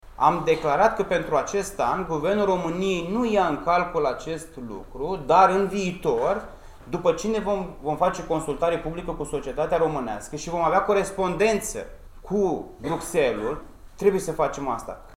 Însă, în cadrul conferinței organizate de către organizația Ambasada Sustenabilității, a fost întrebat dacă Guvernul va introduce taxa de similară la nivel național: